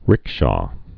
(rĭkshô)